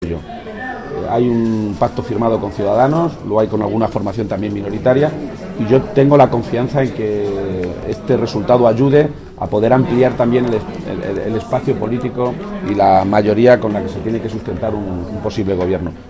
García-Page, que ha realizado estas declaraciones tras votar en la sede socialista de Toledo, ha señalado que aunque haya gente que lo piense, los próximos días 1 y 2 o 5 de marzo “no van a ser un punto final” en el proceso para formar gobierno, “porque todavía quedarán semanas por delante para que el PSOE intente ampliar ese espectro de apoyos”.
Cortes de audio de la rueda de prensa